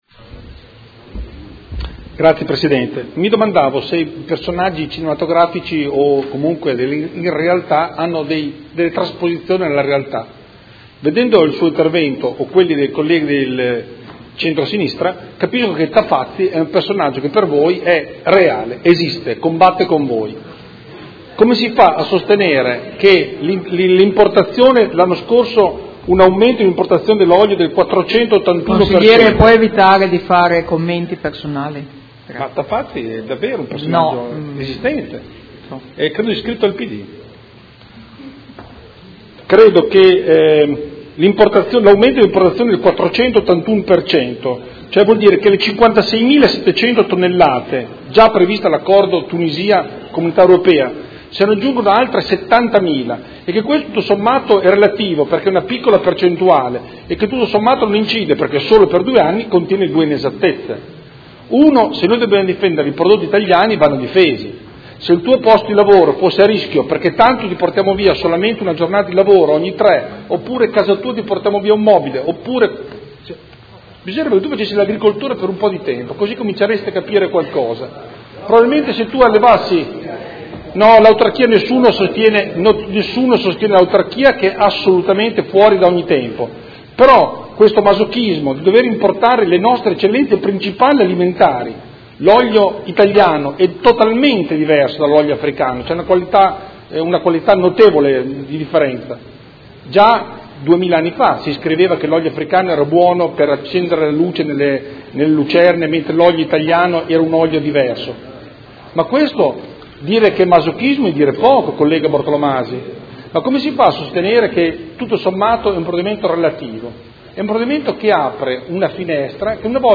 Andrea Galli — Sito Audio Consiglio Comunale
Seduta del 31/03/2016. Ordine del Giorno presentato dal Consigliere Galli (F.I.) avente per oggetto: Sostegno alla produzione olearia italiana; nelle mense modenesi venga usato solo olio italiano, il migliore al Mondo, in modo da favorire l’Agricoltura italiana e il consumo Consapevole e di Qualità.